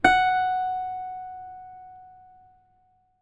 ZITHER F#3.wav